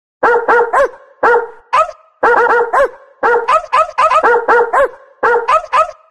Звуки собак
Обработанный лай собак в стиле музыки